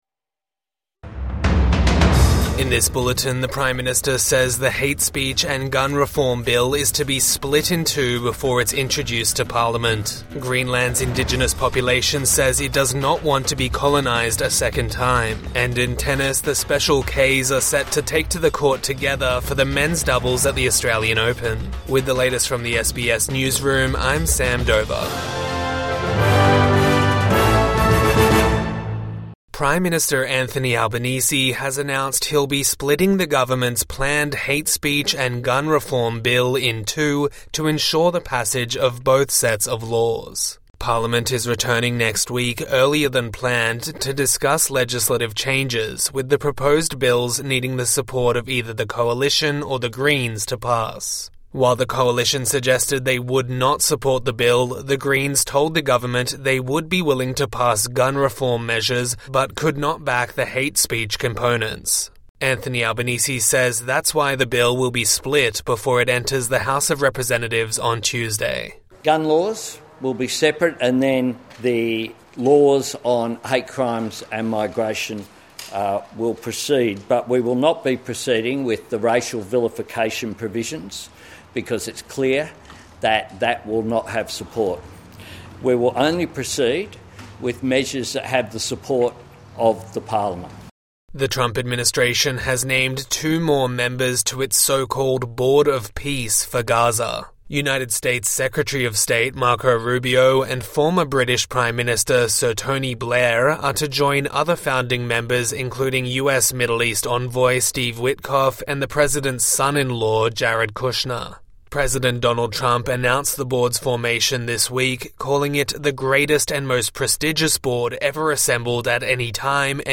Albanese splits hate speech and gun reform bill in two | Evening News Bulletin 17 January 2026